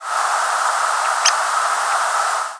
Downy Woodpecker Picoides pubescens
Most frequent call given in flight is a long, high, descending whinny "di-di-di-di-di-di-didududu".
Similar species Whinny call is higher, more musical, and more descending than Hairy Woodpecker's rattle.